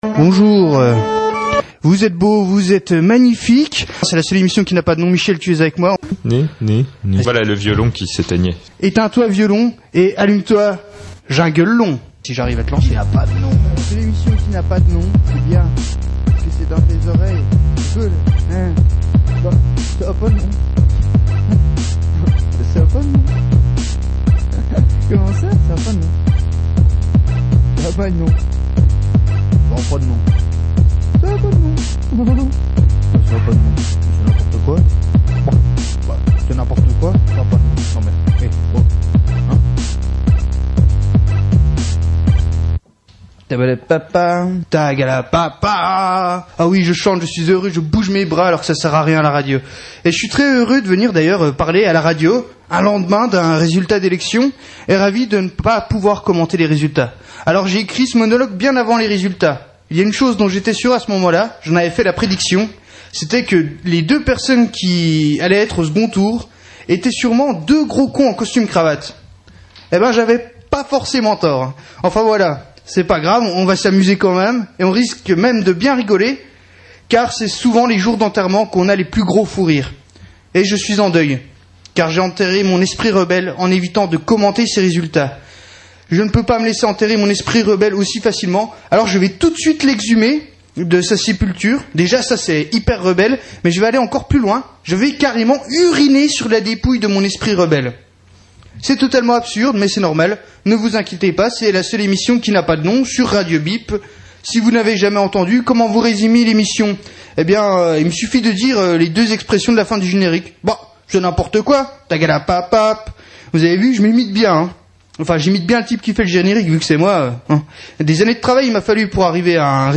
Voici , comme indiqué dans le titre, le podcast de la seule émission qui n’a pas de nom qui fut émise depuis Radio Bip, le 23 avril 2012 entre 10 heures et 11 heures.